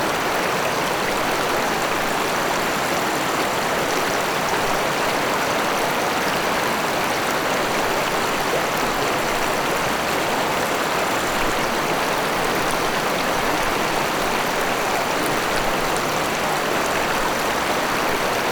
water_river_str.wav